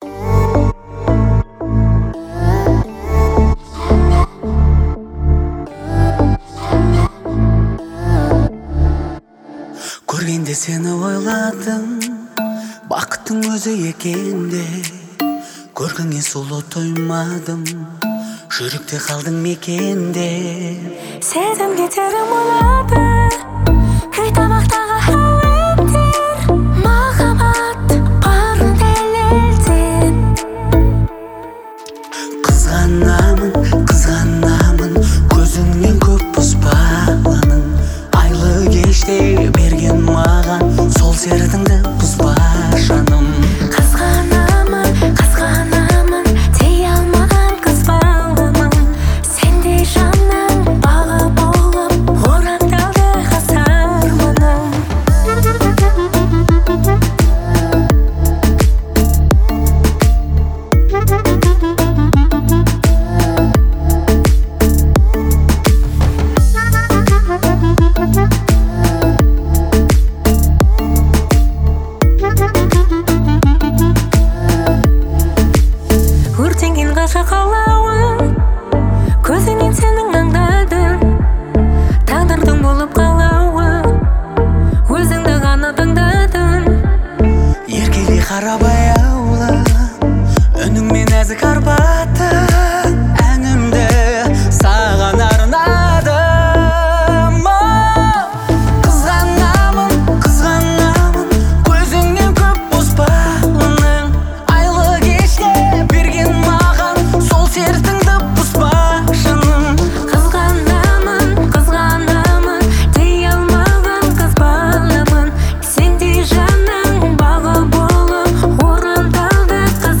это трогательный дуэт в жанре казахской поп-музыки.